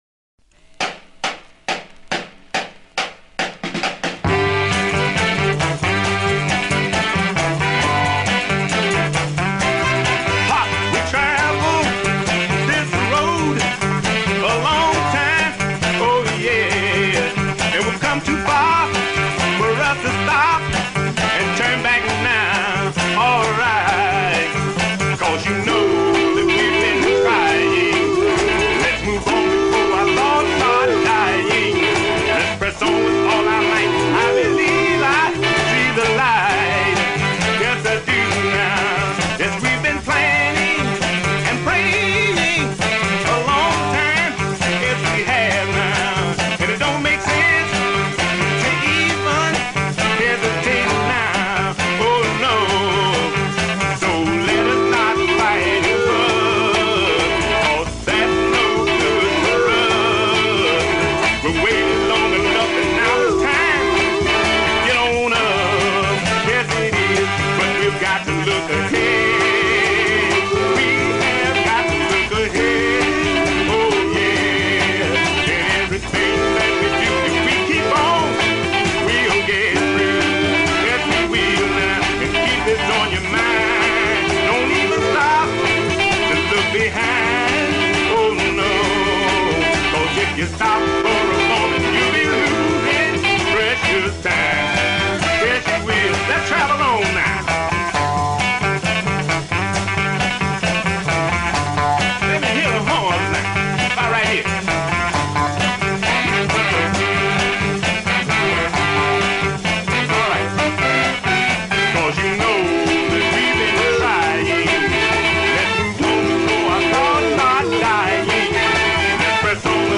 60’s Rare Soul